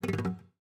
ui_volver.wav